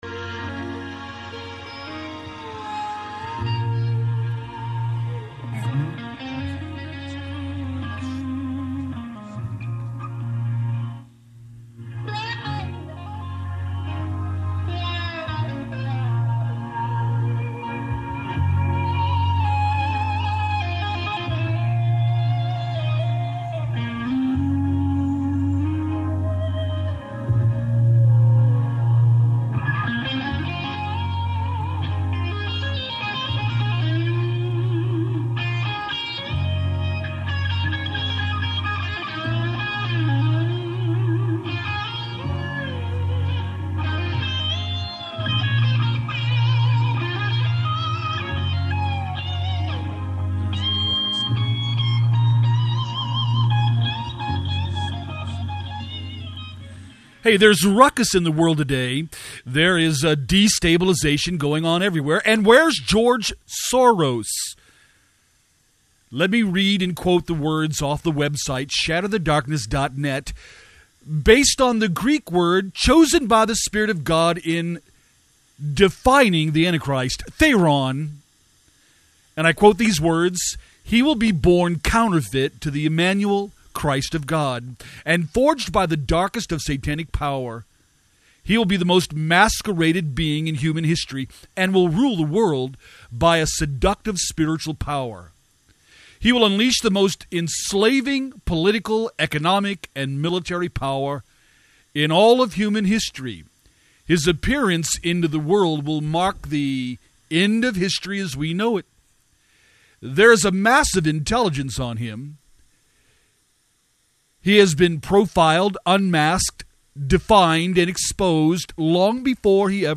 SHATTER THE DARKNESS LIVE RADIO BROADCAST INTERNET, SATELLITE AND ARCHIVES THEME FOR THE WEEK OF FEBRUARY 21st TO FEBRUARY 25TH 2011 STUDY NOTES �THERION� THE BEAST, THE BEAST SYSTEM AND THE �MAN� WHO SHAKES THE WORLD TO ITS CORE!